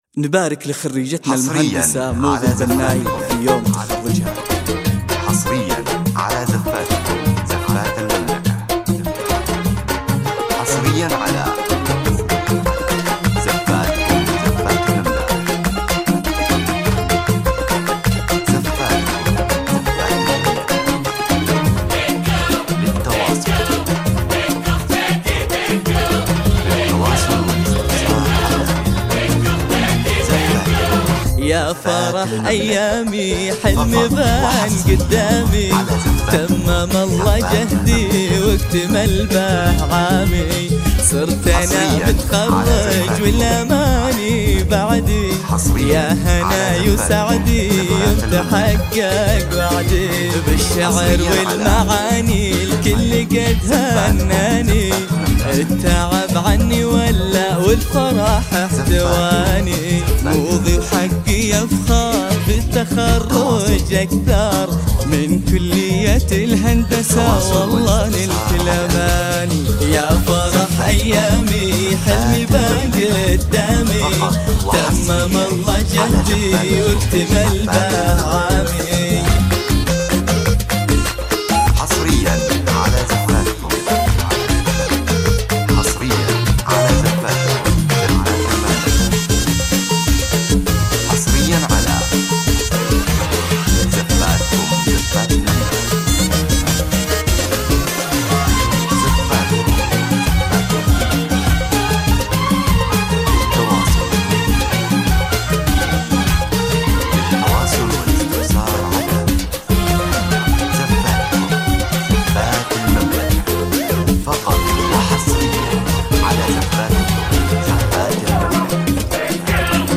زفات تخرج
زفة تخرج موسيقية حصرية
تعكس مشاعر الفخر والإنجاز في لحظات التخرج